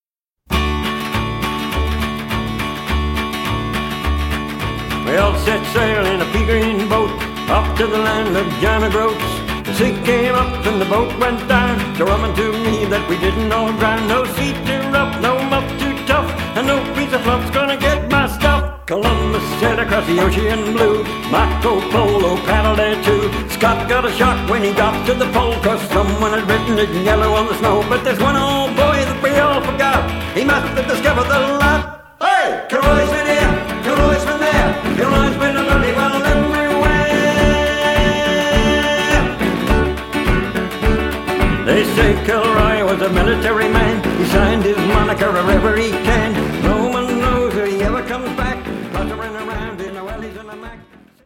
skiffle group